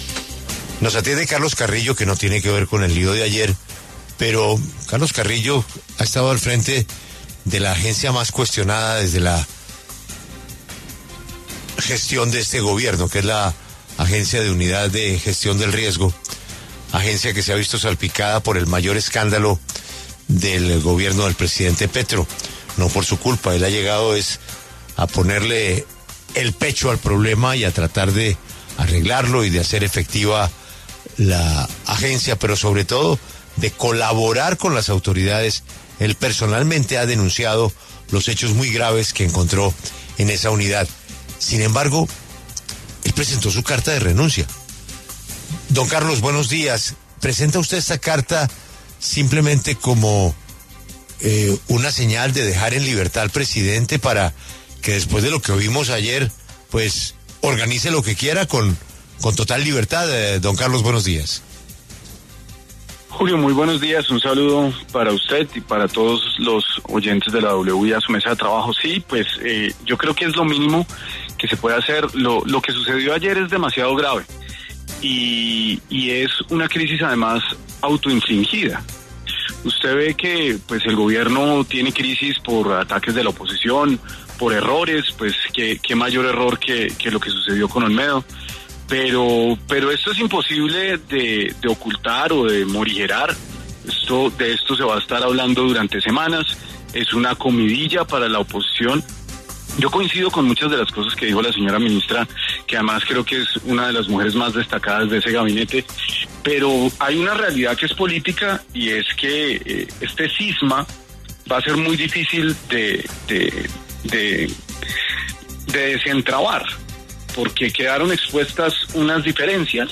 Carlos Carrillo, director de la UNGRD, habló en La W y pidió la renuncia del alto gobierno.